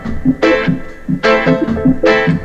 RAGGA LP01-L.wav